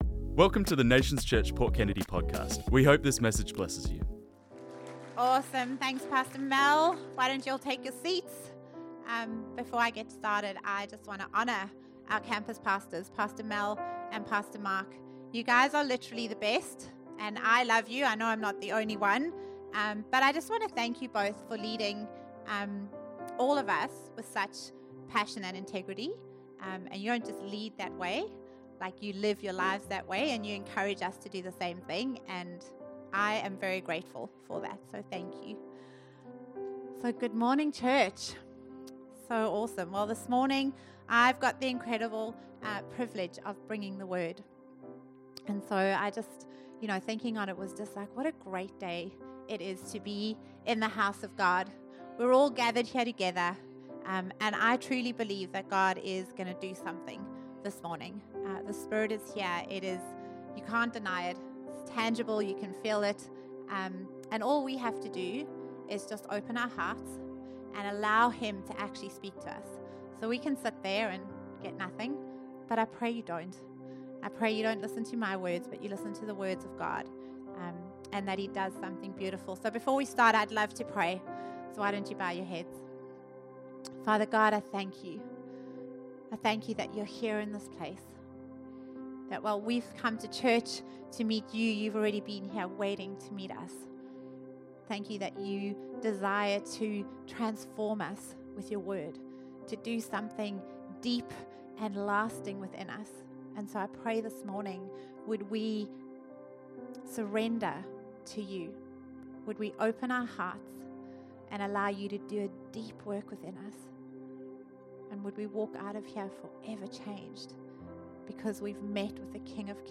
This message was preached on Sunday 23rd March 2025